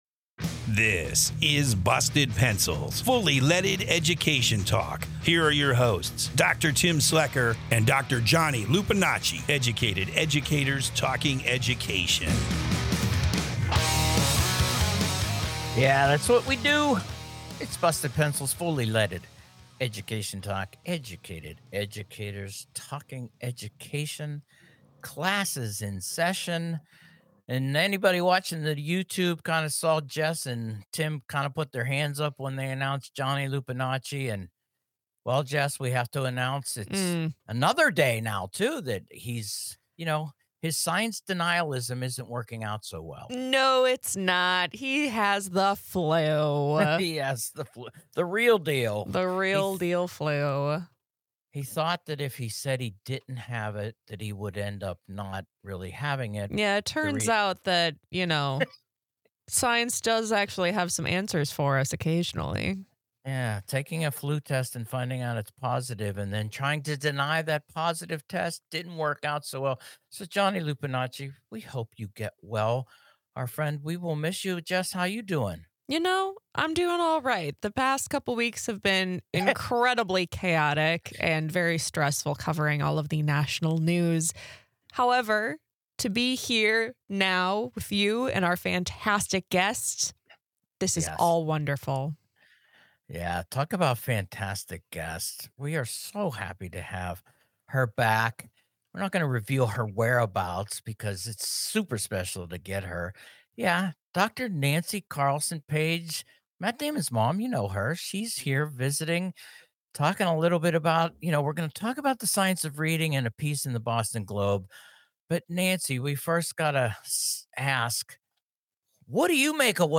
The show establishes an alternative space and discusses education reform from an entirely progressive perspective. Our approach is funny, edgy, passionate, witty and gritty and features an array of guests.